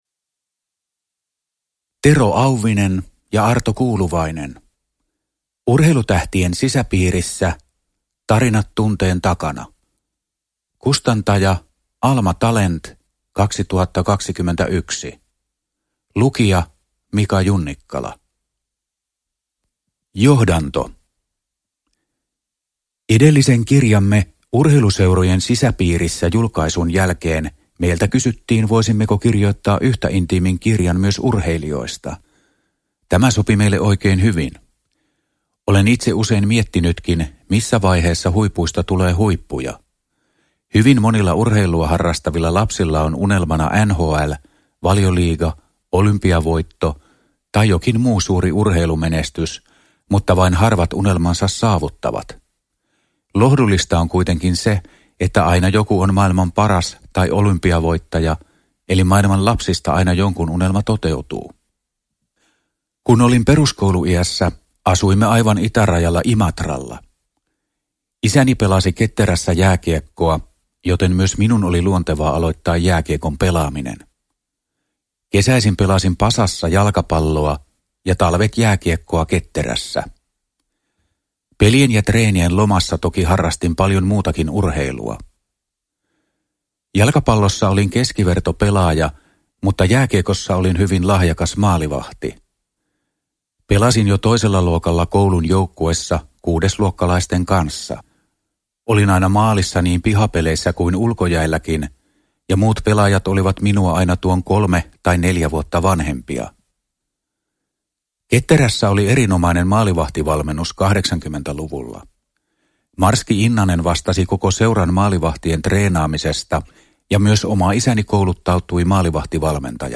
Urheilutähtien sisäpiirissä – Ljudbok – Laddas ner